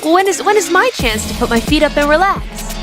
Worms speechbanks
Hurry.wav